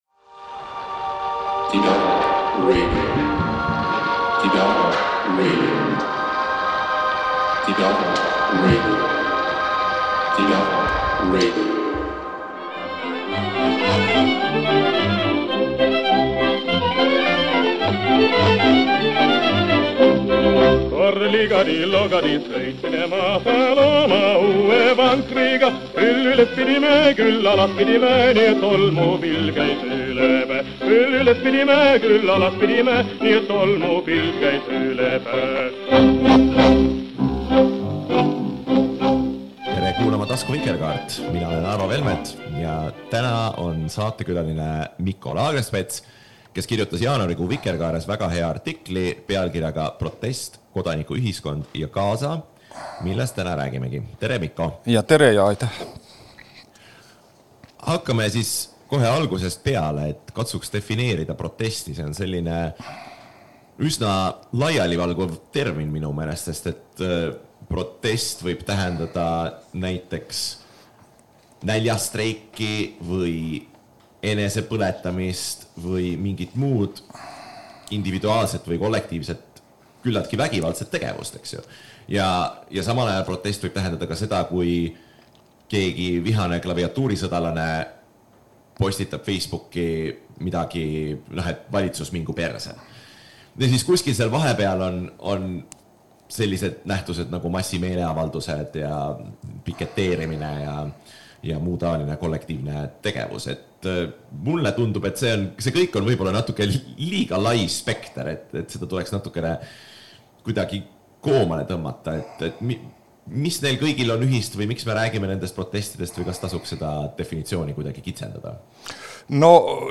helistas kaugekõnega IDA-raadio stuudiosse